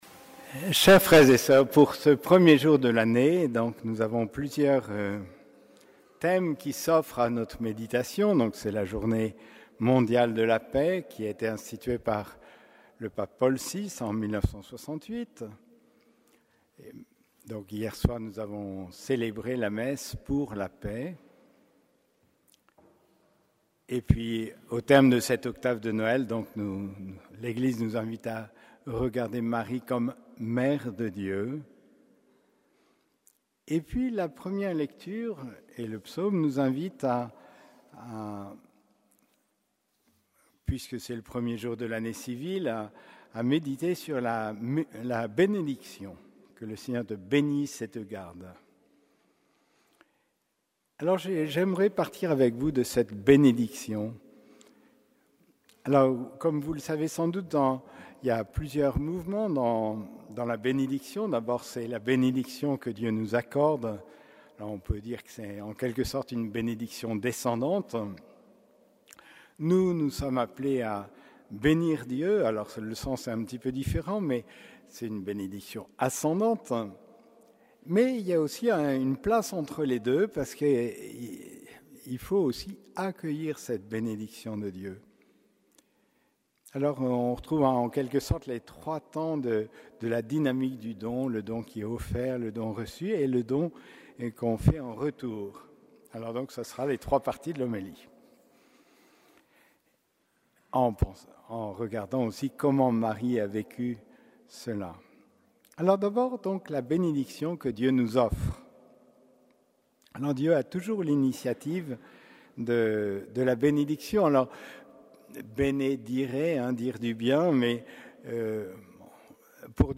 Homélie de la solennité de Sainte Marie, Mère de Dieu